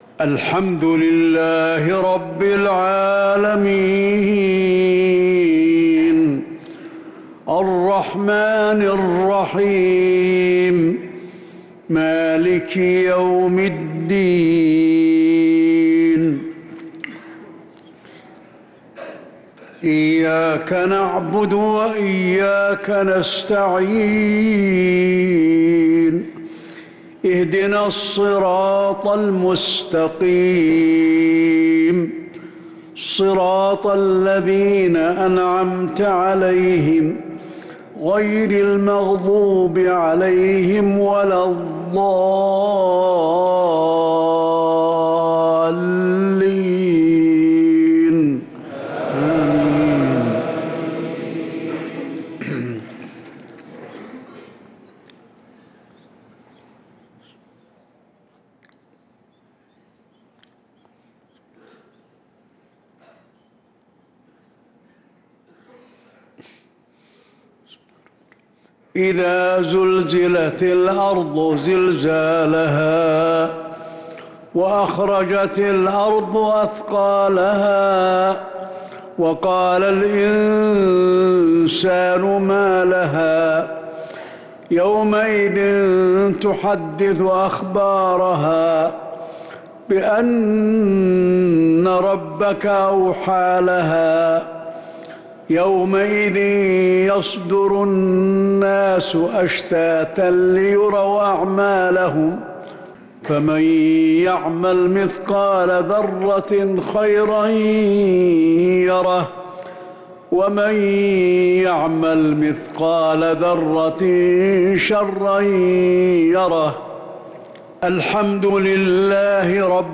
مغرب الإثنين 3-9-1446هـ سورتي الزلزلة و التكاثر كاملة | Maghrib prayer Surat Az-Zalzala & at-Takathur 3-3-2025 > 1446 🕌 > الفروض - تلاوات الحرمين